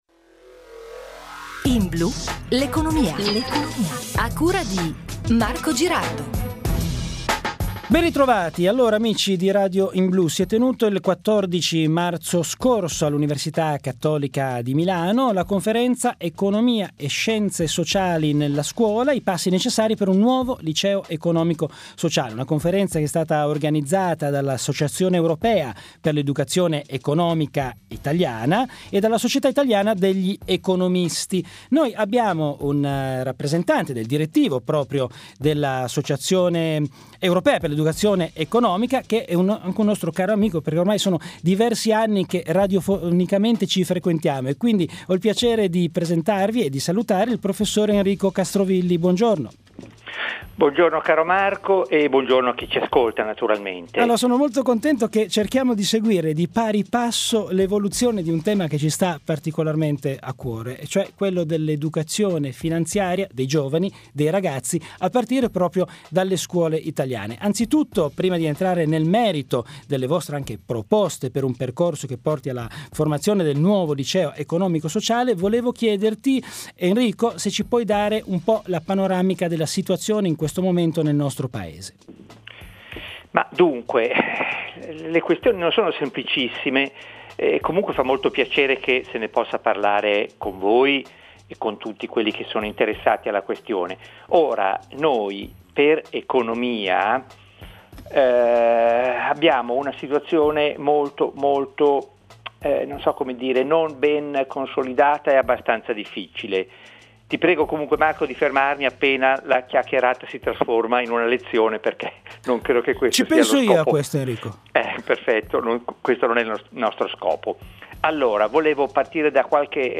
intervista.mp3